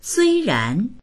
suīrán 虽然 3 ～ではある（けれども…）
sui1ran2.mp3